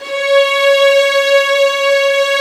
ZG3 STRS C#4.wav